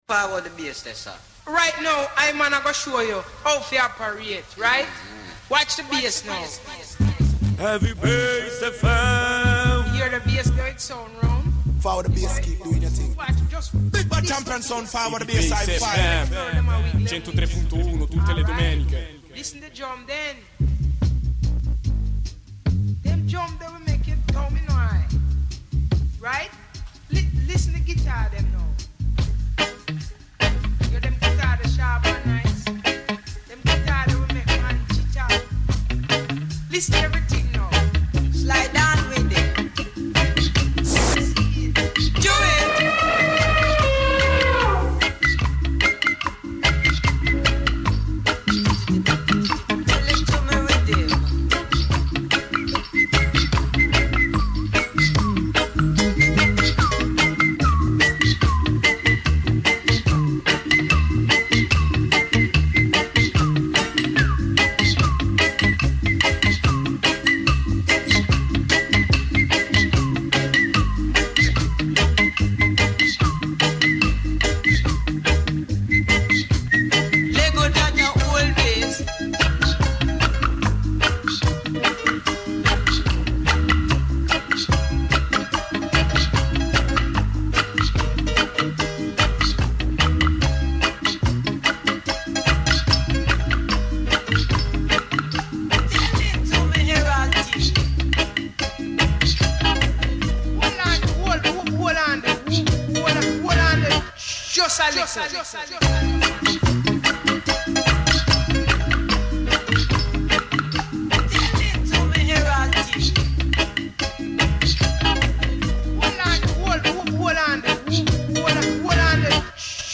Reggae Dancehall Show